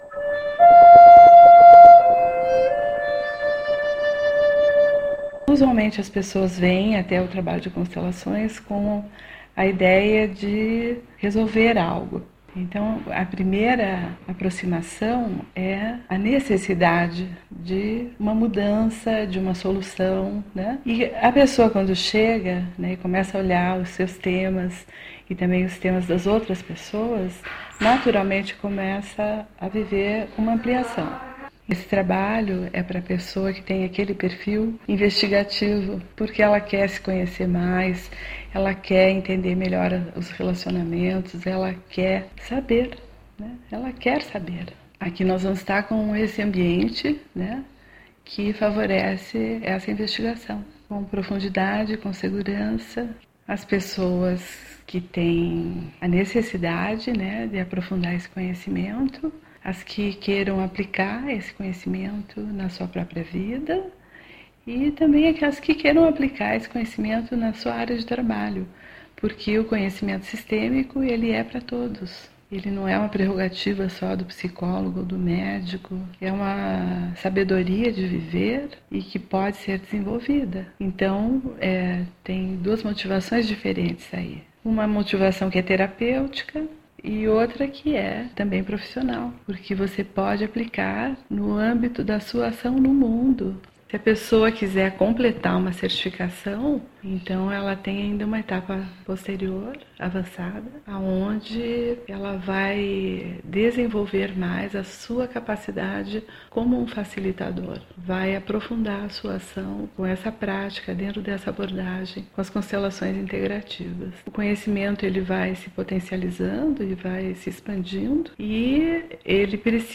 “Relaxing Flute Tune”.